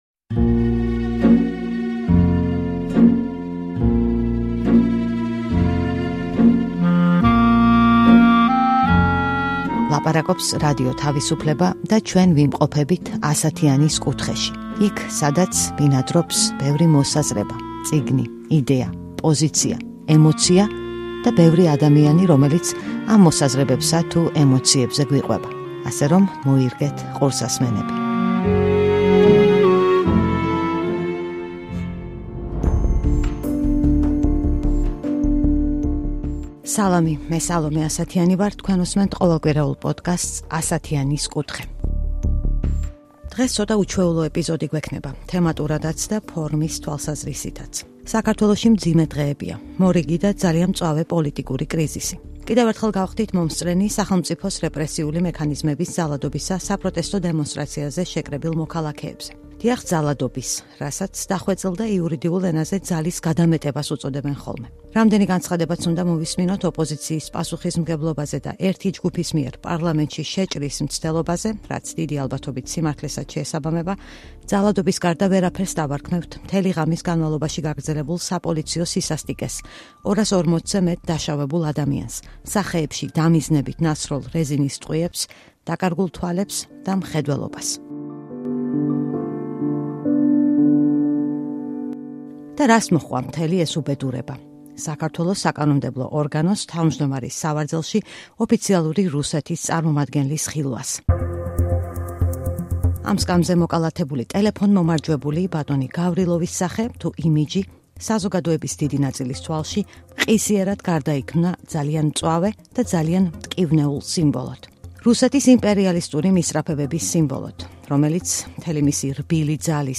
რუსეთი, საქართველო და იმპერიალიზმის ლაბირინთები: ინტერვიუ კოლეგებთან